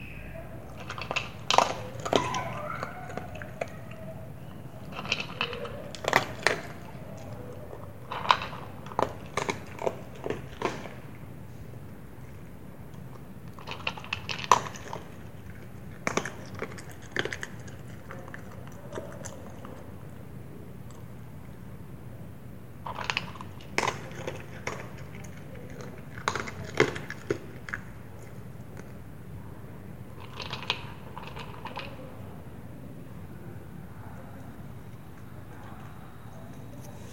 描述：Alta fidelidad：Perros comiendo concentrado。 Registro sonoro el Lunes 01 de Febrero de 2016，enJamundí，Colombia condispositivomóvilSamsungGrand PrimeconaplicaciónPCMRecorder。 高保真：狗吃浓缩液。 2016年2月1日星期一录音带有移动三星Grand Prime PCM录音机应用程序。